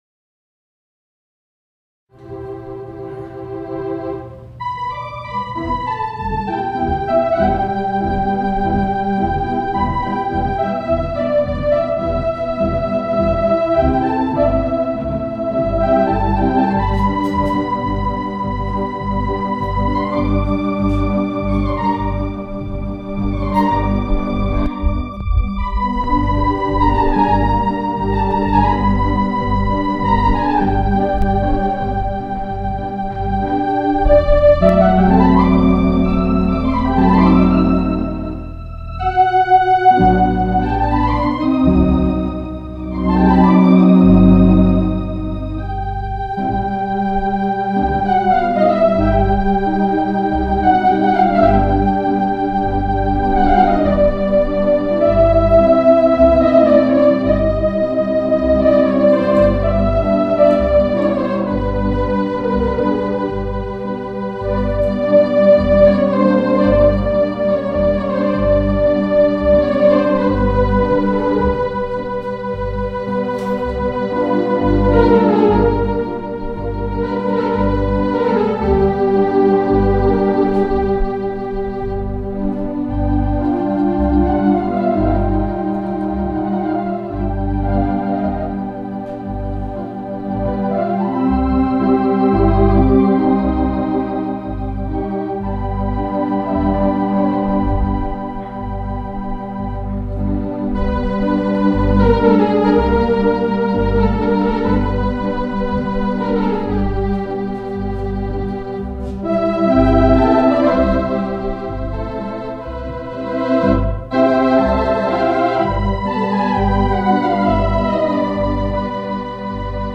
With two outboard Lesie speakers, the sound was grand as it reverberated 'round the huge metal climate controlled equipment barn where it lives.
Performing At Kennedy Meadows
analogue electronic theatre organ